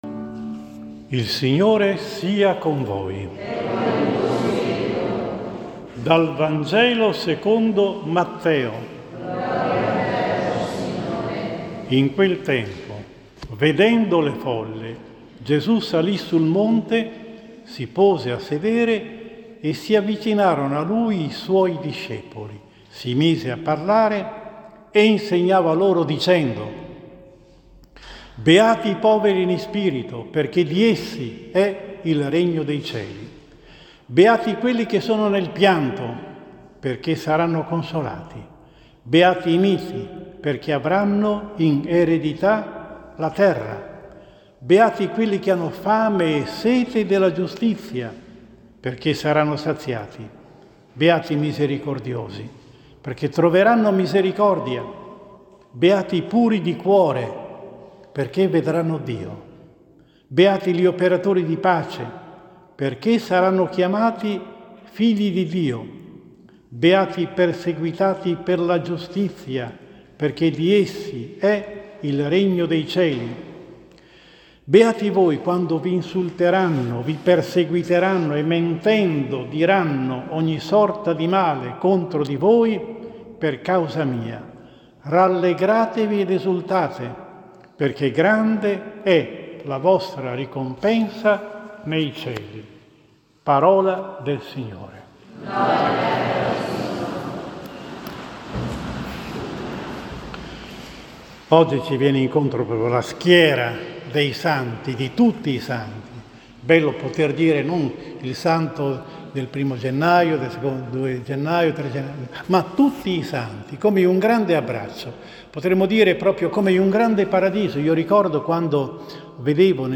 Martedì 1 Novembre 2022, TUTTI I SANTI: omelia